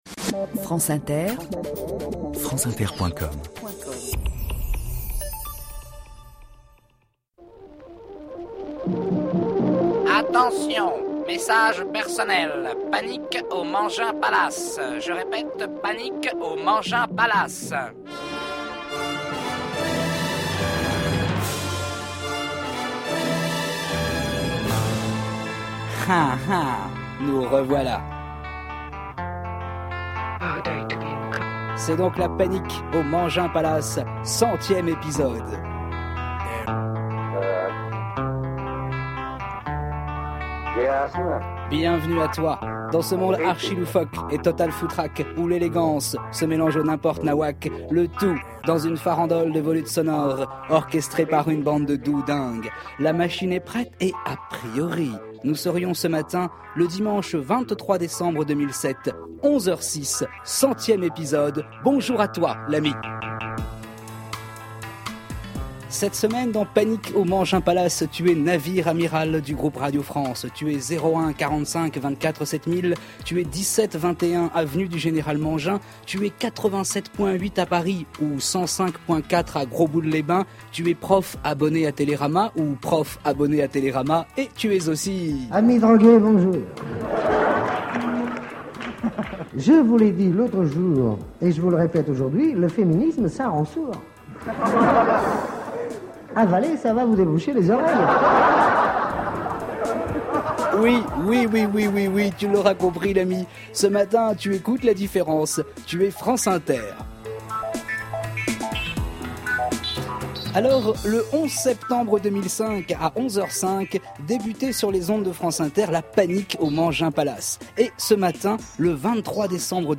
Tous les dimanches sur France Inter à 11 heures et en direct est programmé "Panique au Mangin Palace" :